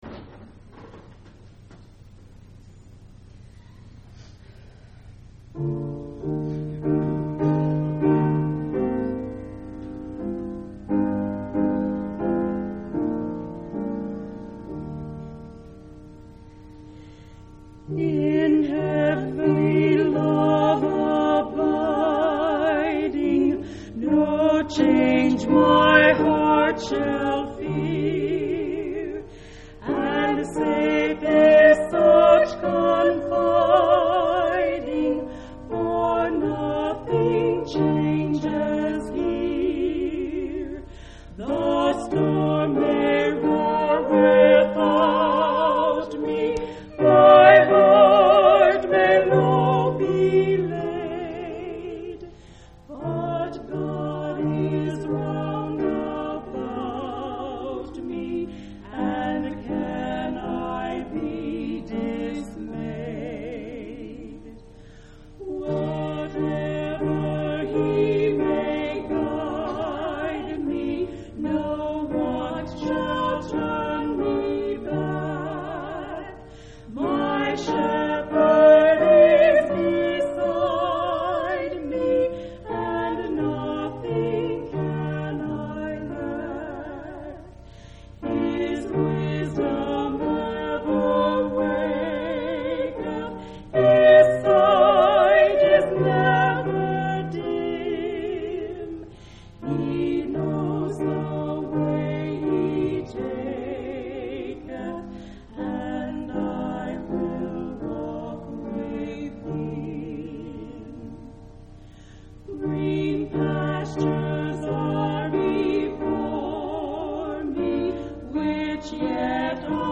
Event: General Church Conference